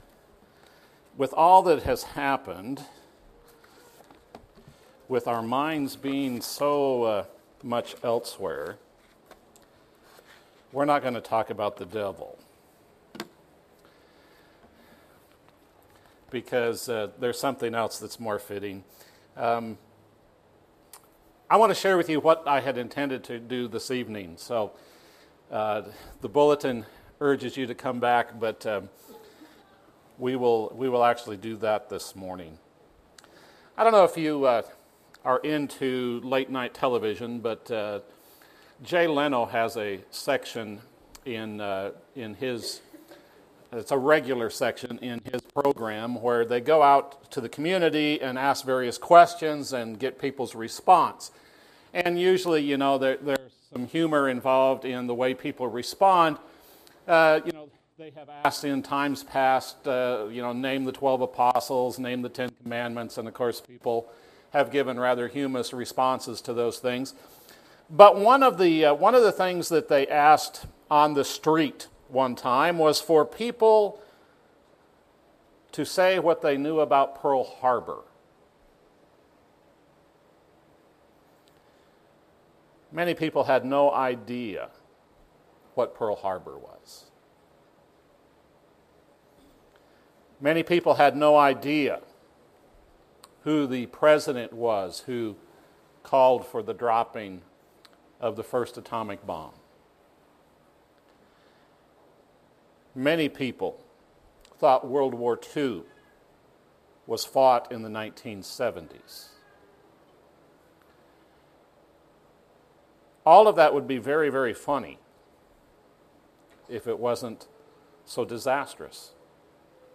This is a lesson on the power of remembering which was used to set the stage for a special “Hall of Faith” service on May 27th. We do need to remember because memories keep hearts and minds alive.